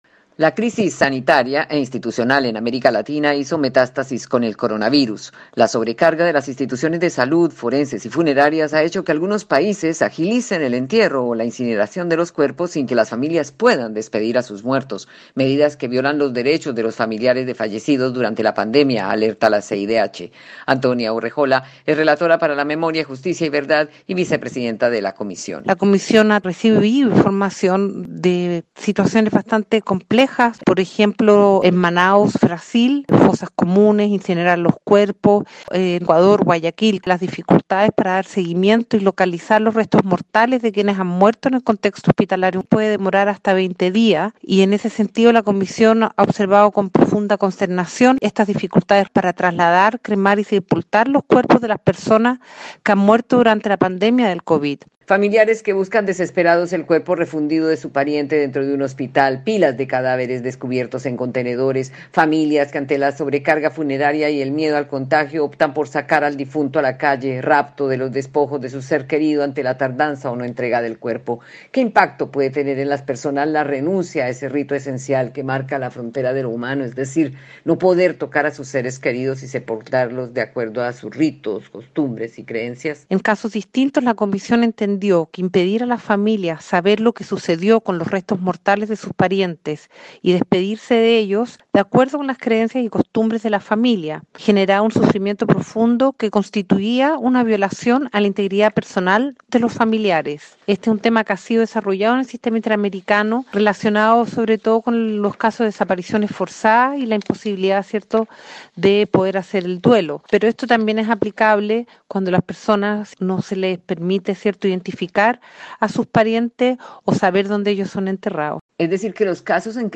« Cuando no se adoptan los procesos adecuados que permiten la identificación de las personas fallecidas o trazar donde están sepultadas, se dificulta a los familiares el tener conocimiento sobre el destino del fallecido y el que puedan vivir su proceso de duelo» afirma Antonia Urrejola, relatora para Memoria, Justicia y Verdad y vice presidenta de la de la CIDH, en entrevista con Radio Francia Internacional.